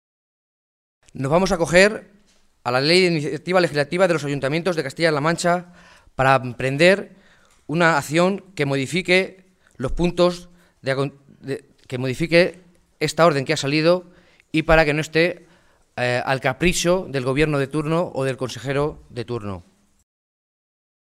Rueda de prensa de los distintos alcaldes socialistas afectados por el cierre de los PAC en la Región
Cortes de audio de la rueda de prensa
Audio alcalde de Tembleque-1